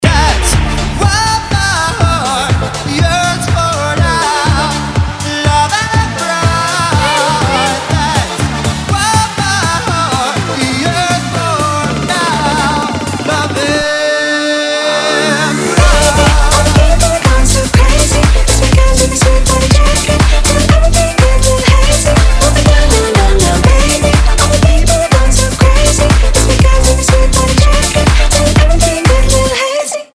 dance
club
vocal